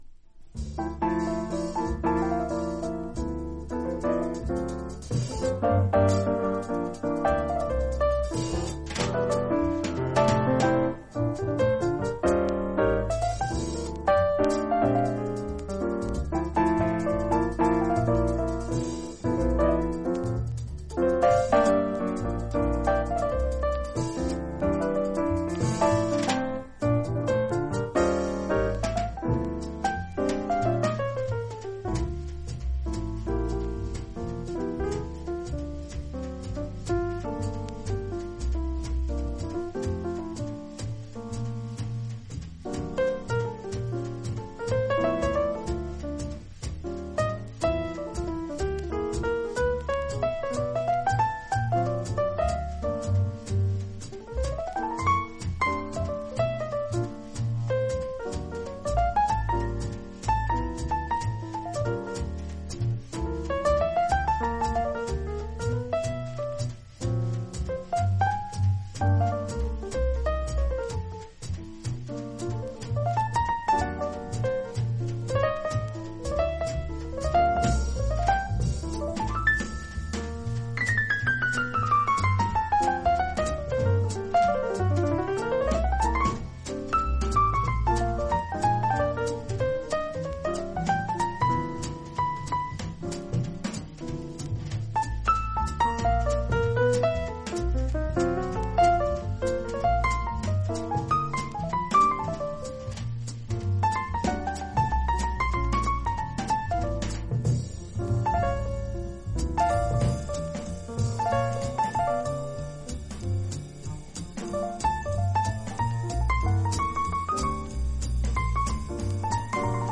実際のレコードからのサンプル↓ 試聴はこちら： サンプル≪mp3≫